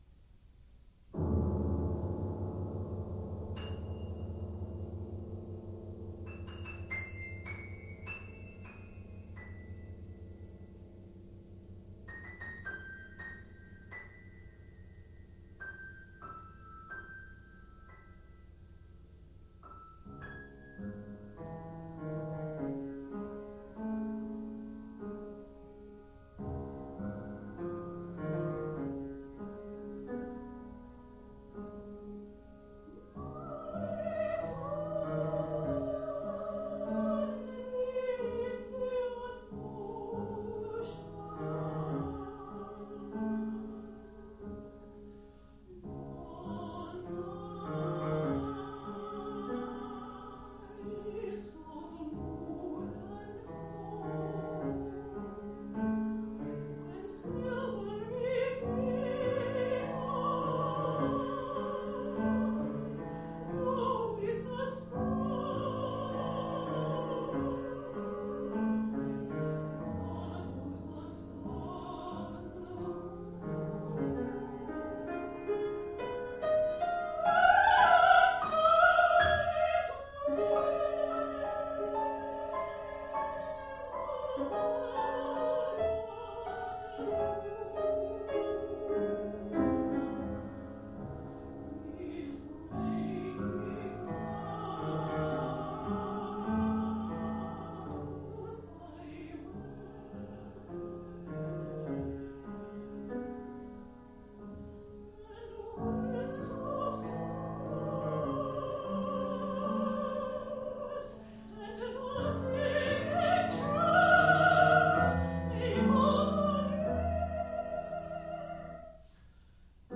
SONG CYCLES